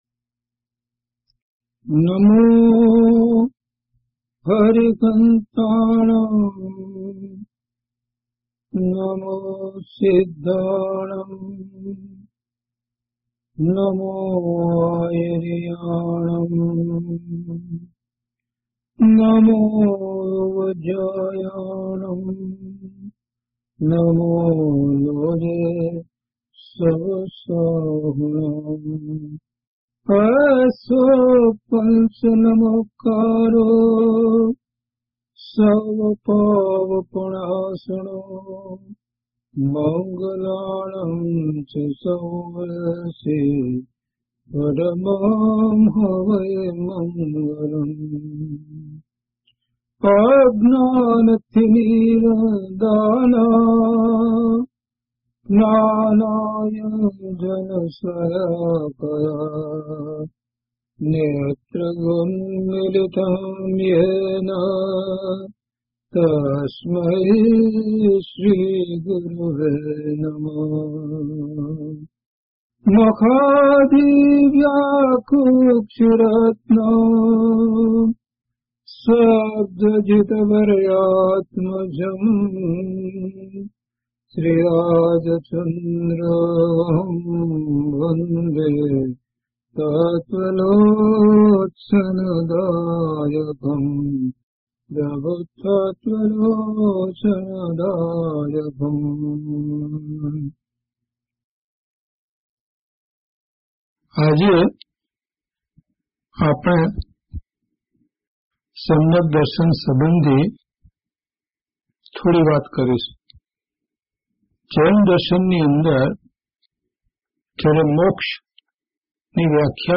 DHP076 Samyag Darshan (Chha Pad) part-1  - Pravachan.mp3